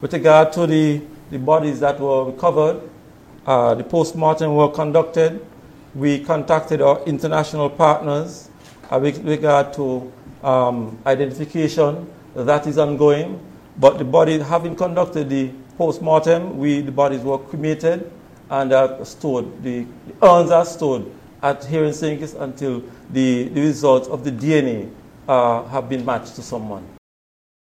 On July 3rd, Members of the High Command of the Royal St. Christopher and Nevis Police Force provided an update. This is Commissioner James Sutton: